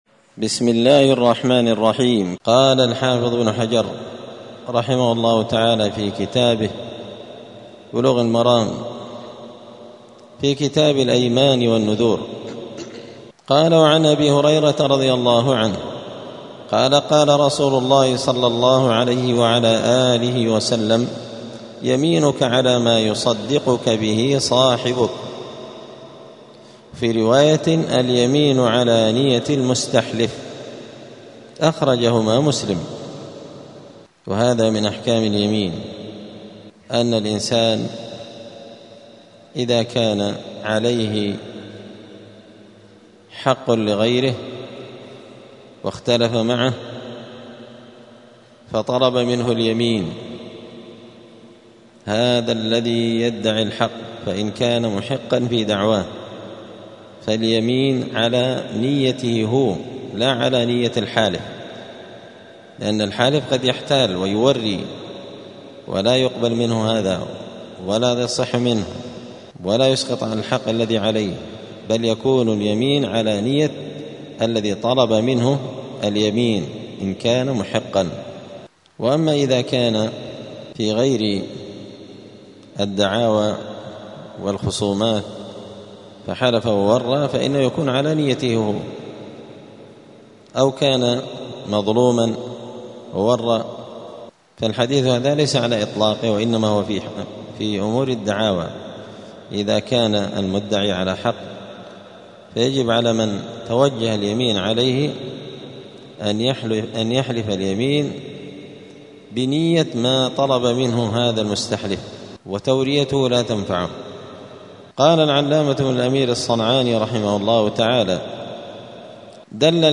*الدرس الثالث (3) {اليمين على نية المستحلف}*